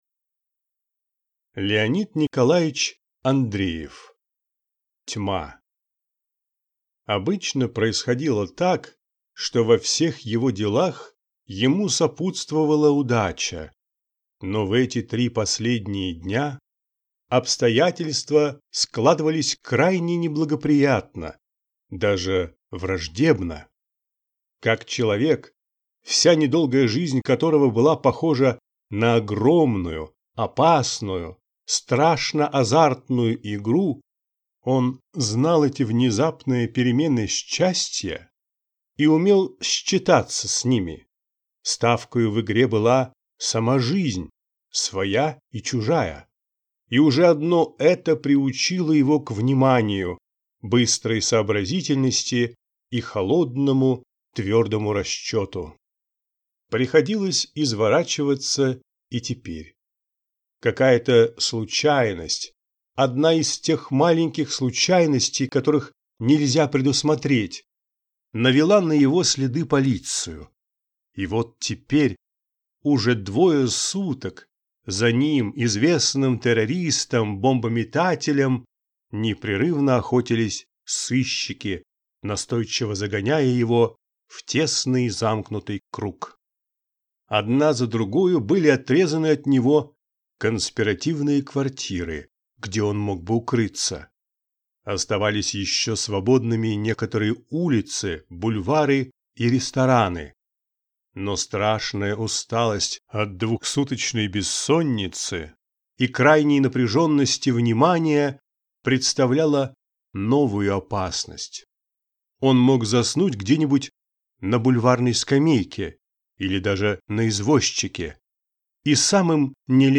Аудиокнига Тьма | Библиотека аудиокниг